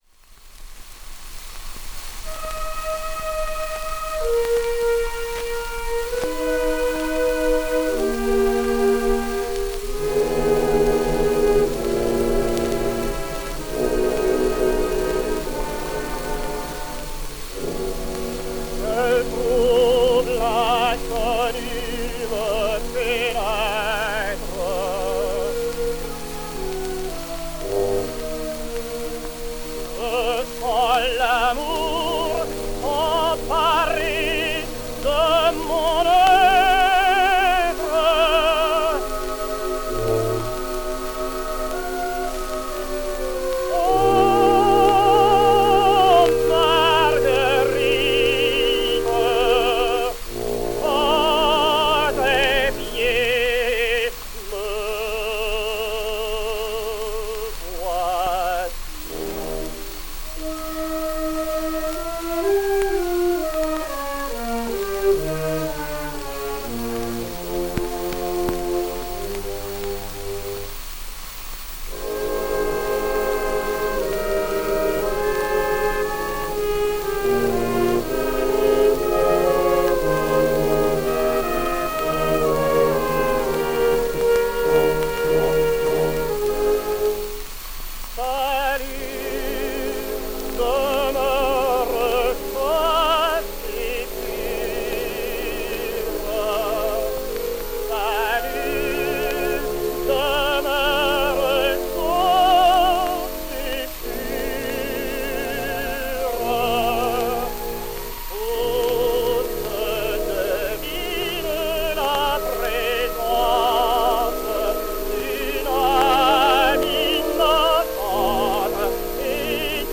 Aérophone, Paris, 1910